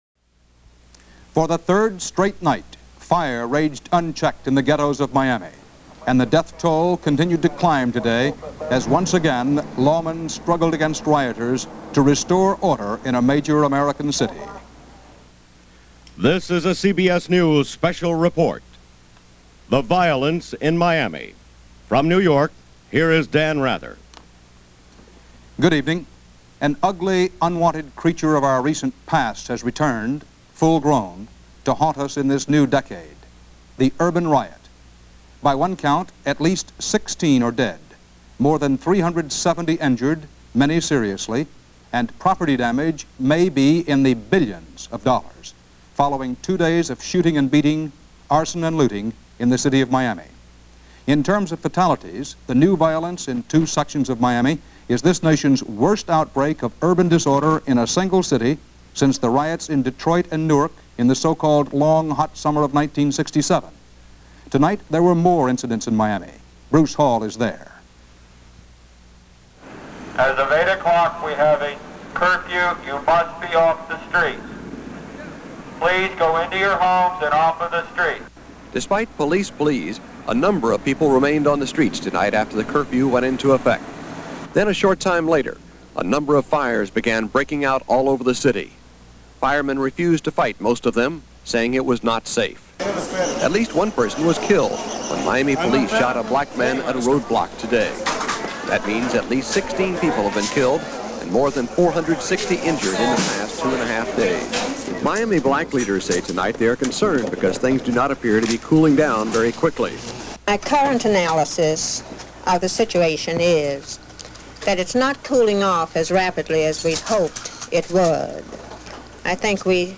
CBS Special Report – Miami Riots – May 19, 1980